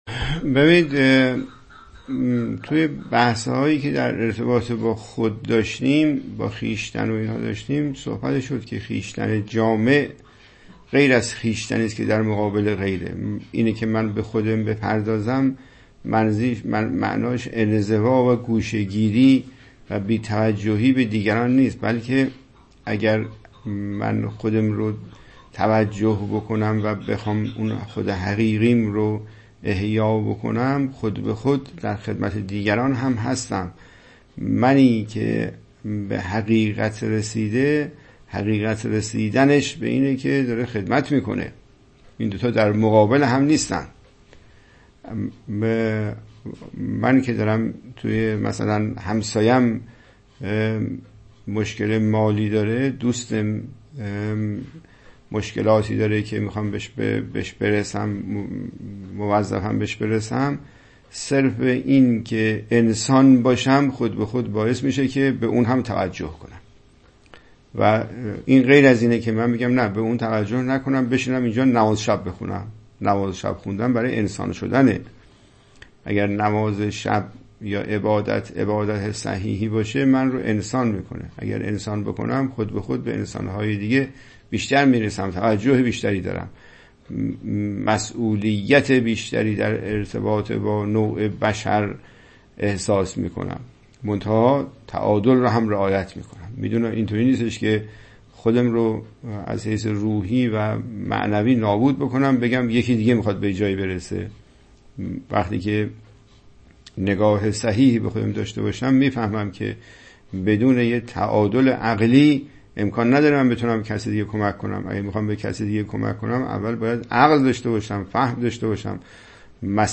گفت‌وگو